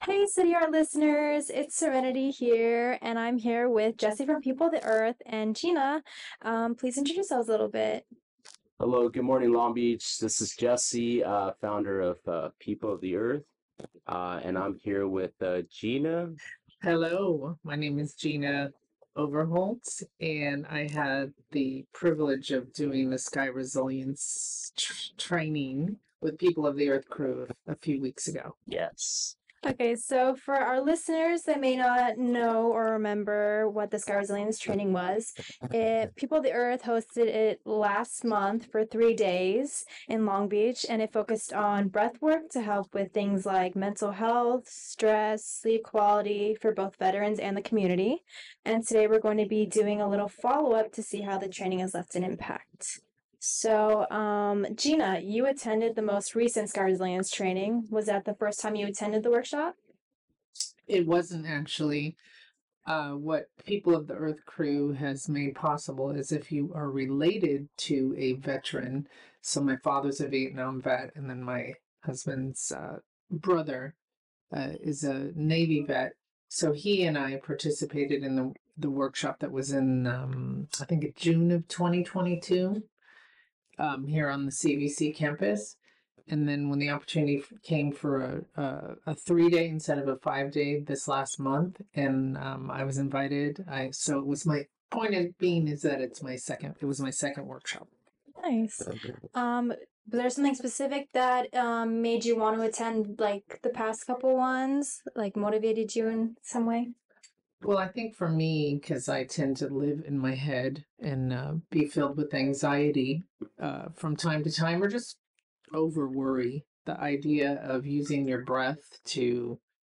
Below includes a preview of some of the topics discussed in the interview.